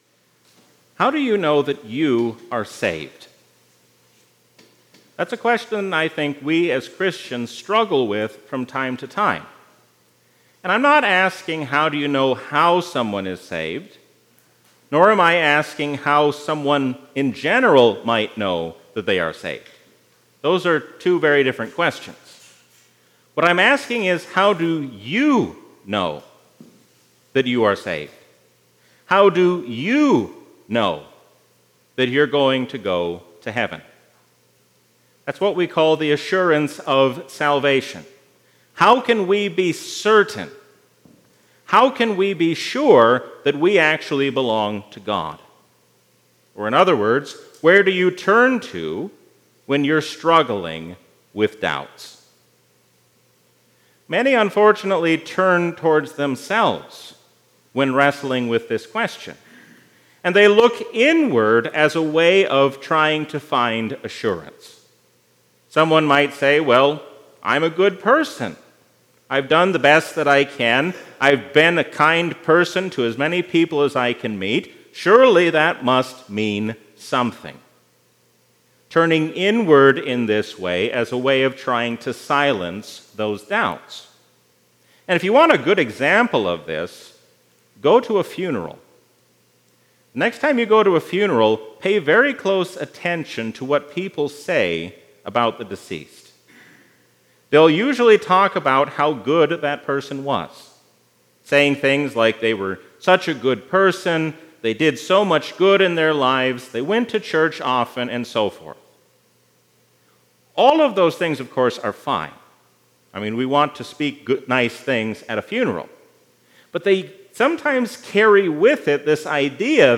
A sermon from the season "Easter 2023." Knowing who the Holy Spirit is changes how we live as Christians.